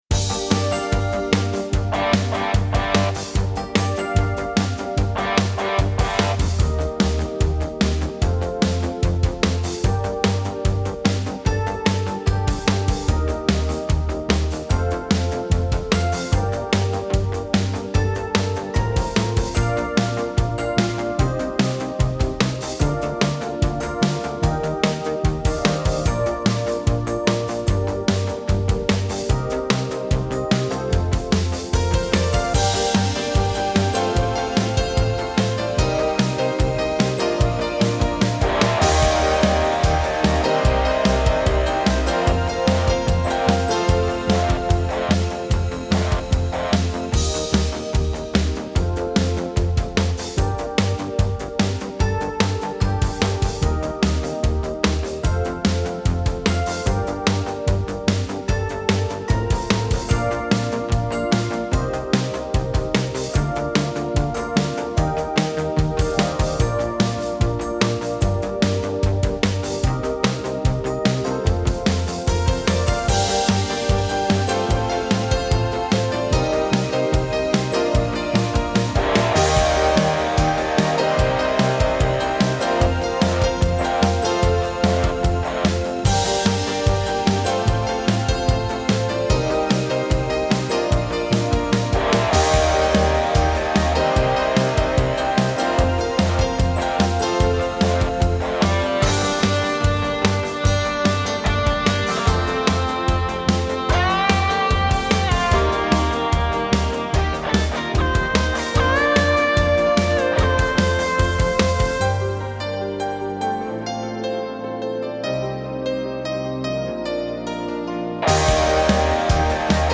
Übungsaufnahmen - Dein ist mein ganzes Herz
Dein ist mein ganzes Herz (Playback)
Dein_ist_mein_ganzes_Herz__5_Playback.mp3